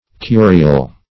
Search Result for " curial" : The Collaborative International Dictionary of English v.0.48: Curial \Cu"ri*al\, a. Of or pertaining to the papal curia; as, the curial etiquette of the Vatican.
curial.mp3